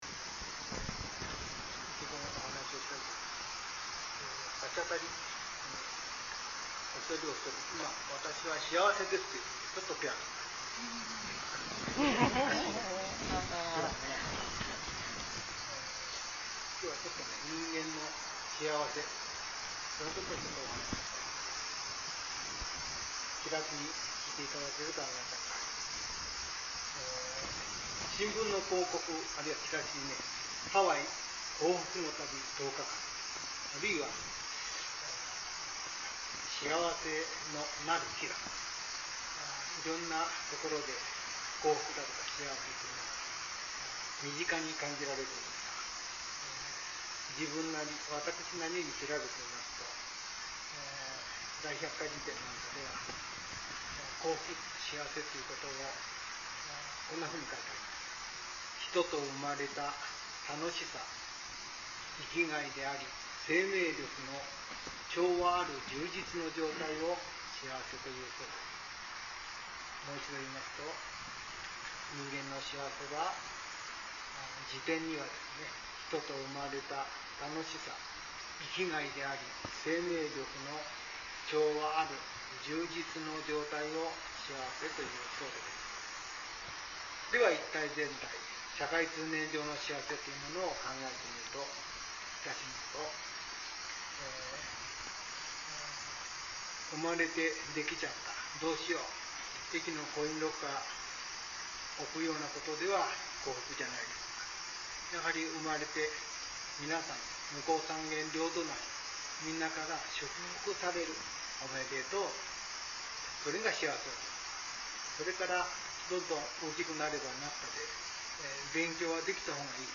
It was the listening of sutra inquiry and the lecture of the Zen Buddhism in the temple following grave cleaning and a visit to a grave.
幸せ講話
幸せ講話.mp3